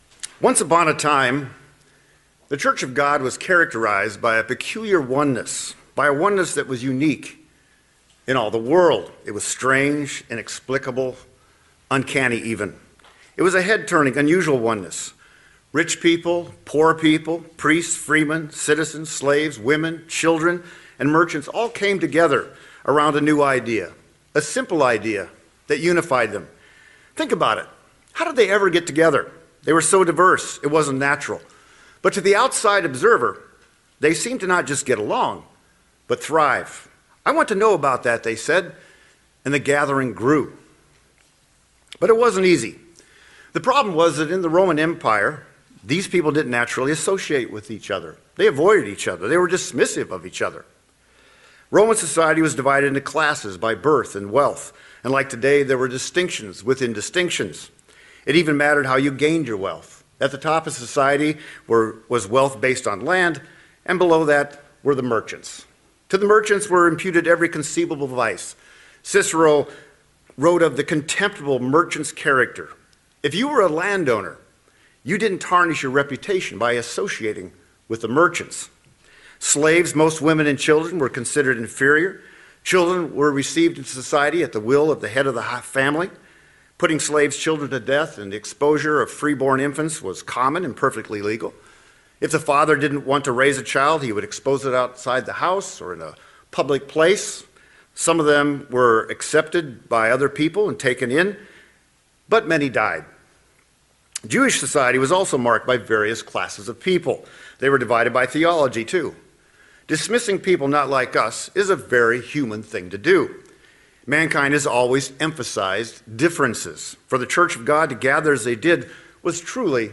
Sermons
Given in San Diego, CA Redlands, CA Las Vegas, NV